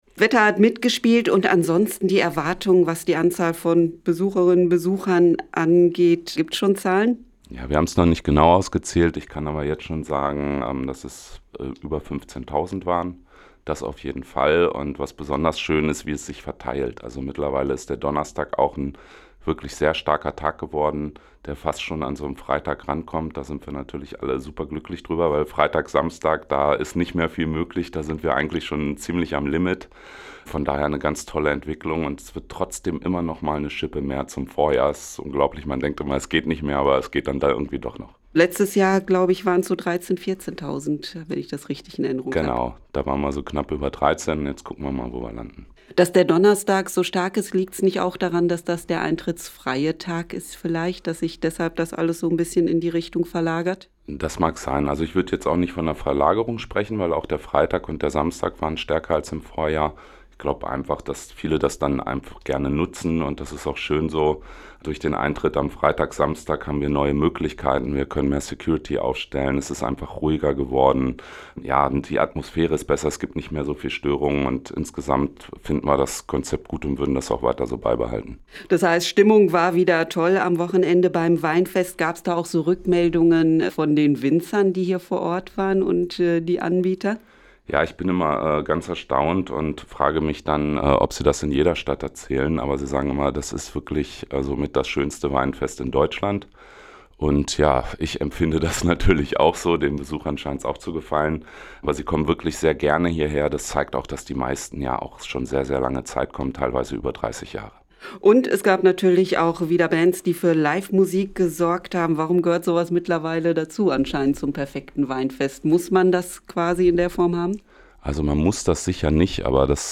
Hameln: Gute Stimmung beim Weinfest 2024